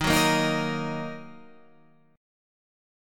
D# Minor